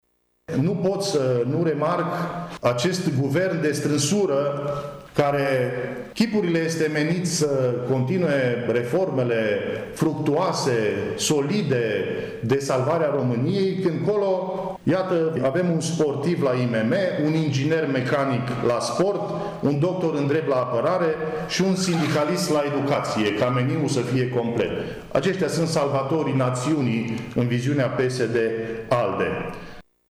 Marius Pașcan a criticat astăzi, într-o conferință de presă, componența noului executiv.